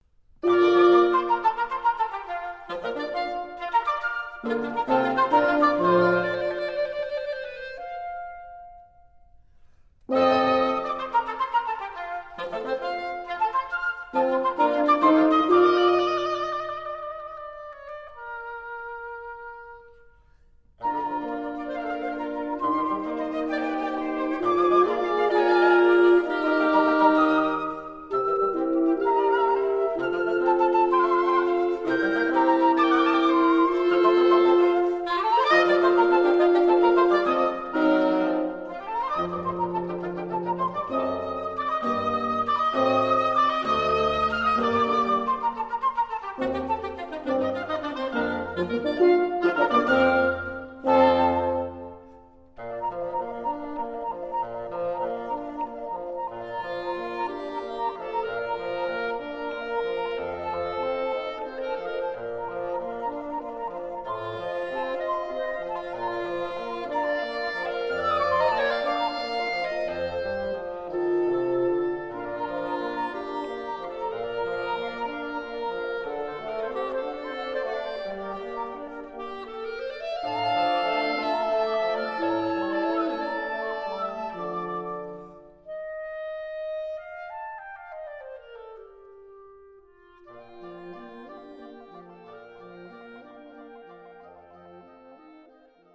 For Woodwind Quintet